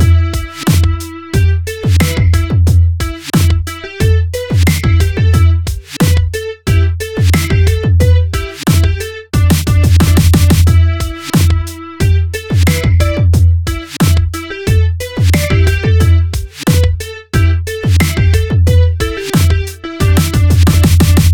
This is a short upbeat loop.